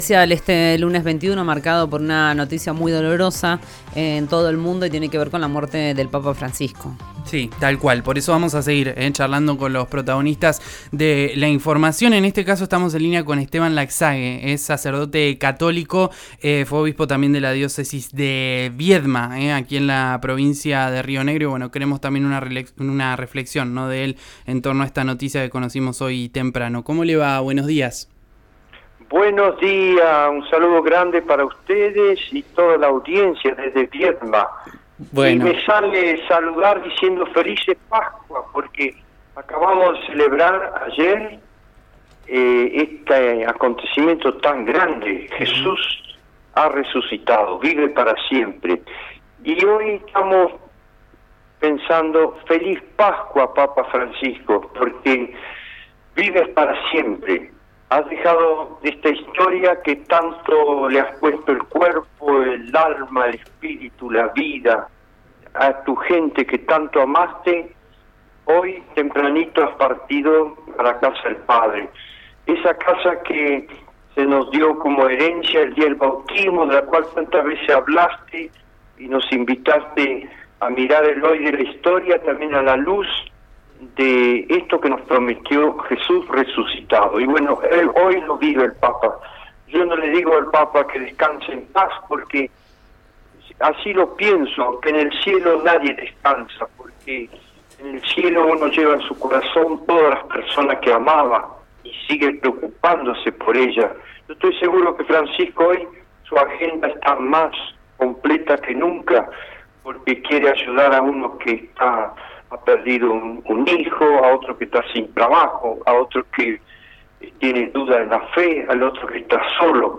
Esuchá a Esteban Laxague, obispo de Viedma, en RÍO NEGRO RADIO
En diálogo con RÍO NEGRO RADIO el obispo compartió su sentida reflexión sobre la partida del jefe de la Iglesia Católica, una noticia que lo tomó de imprevisto, como a todos.